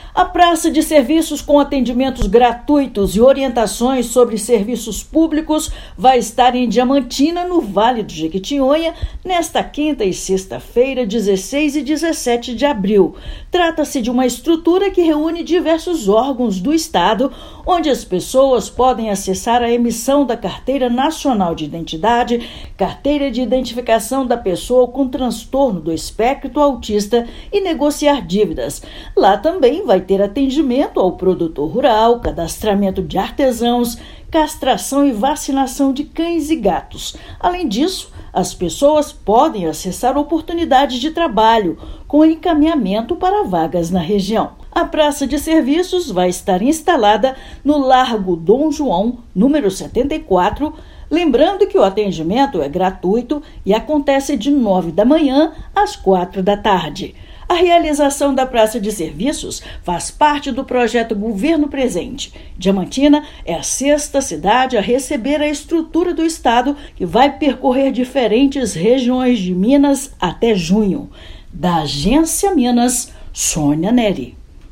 Projeto reúne emissão de documentos, serviços de saúde, apoio ao produtor rural e atendimentos essenciais em um único espaço. Ouça matéria de rádio.